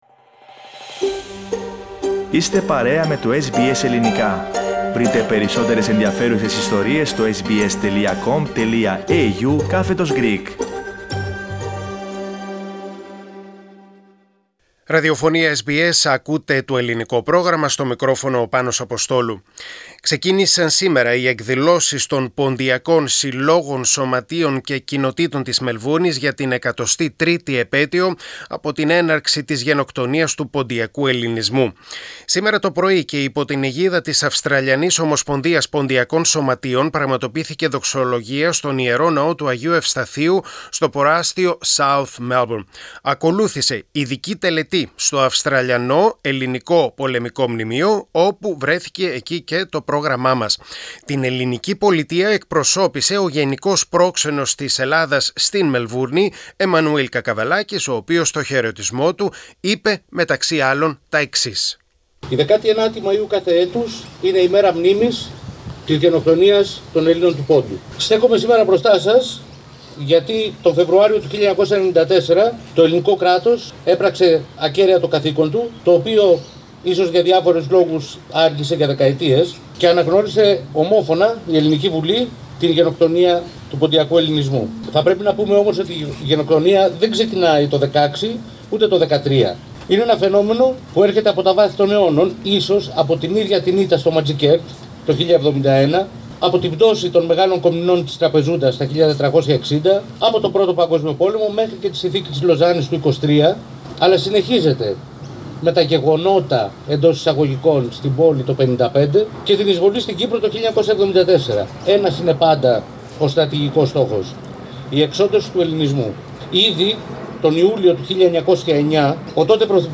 Ακολούθησε ειδική τελετή στο Αυστραλιανό Ελληνικό Μνημείο όπου βρέθηκε το Πρόγραμμά μας.